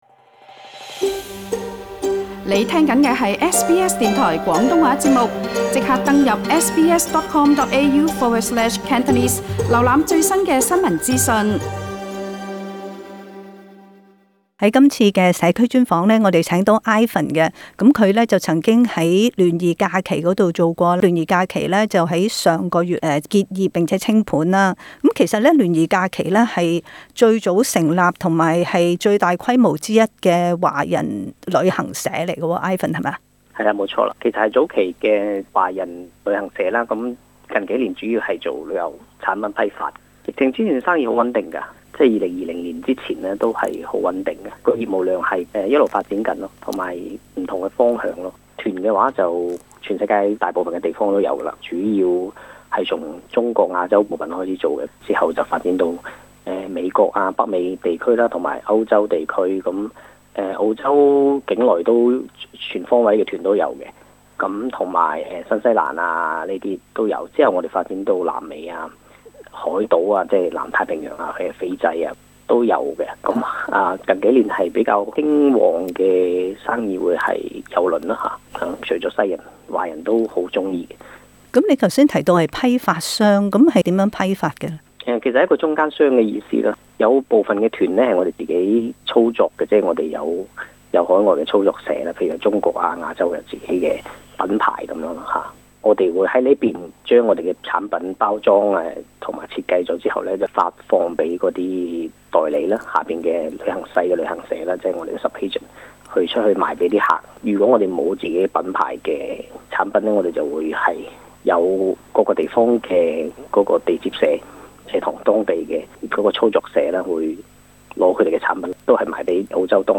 【社區專訪】